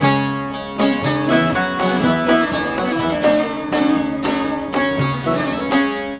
SAZ 01.AIF.wav